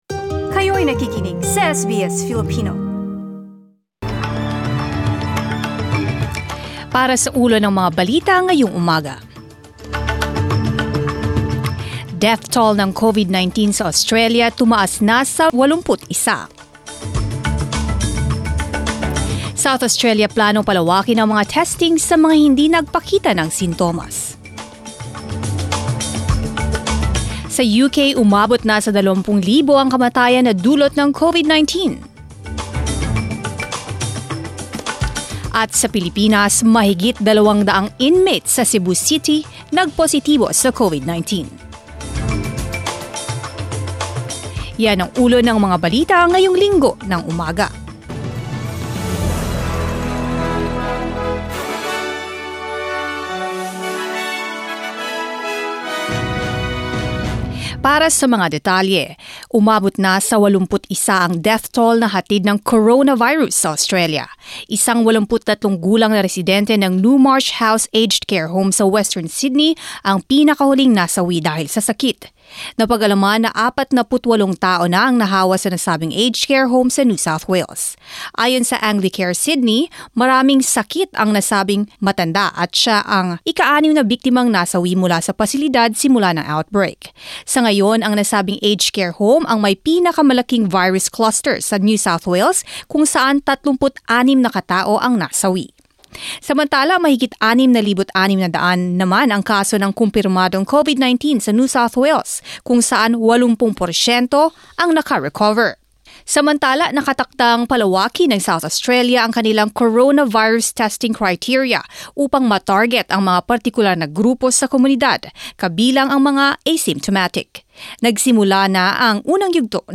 SBS News in Filipino, Sunday 26 April